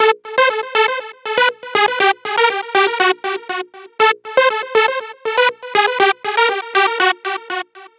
TSNRG2 Lead 016.wav